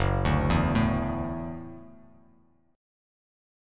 Listen to Gbm7#5 strummed